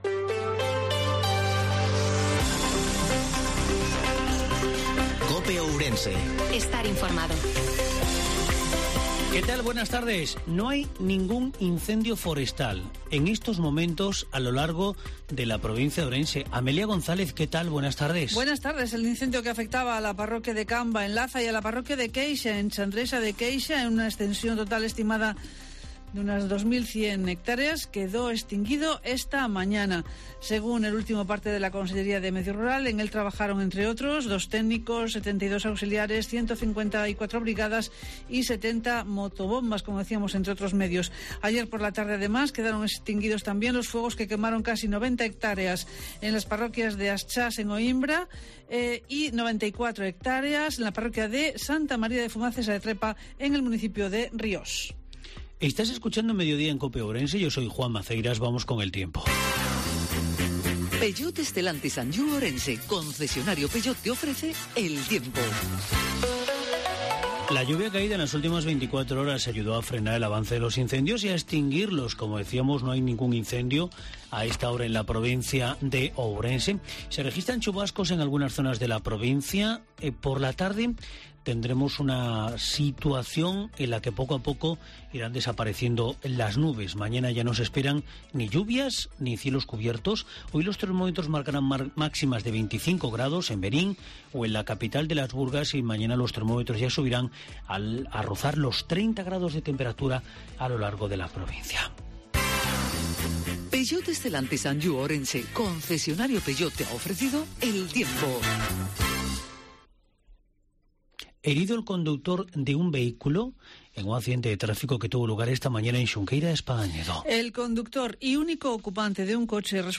INFORMATIVO MEDIODIA COPE OURENSE-17/08/2022